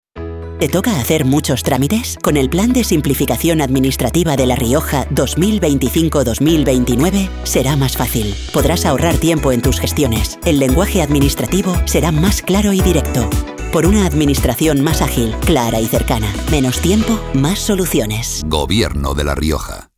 Cuñas radiofónicas
Cuña